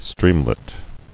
[stream·let]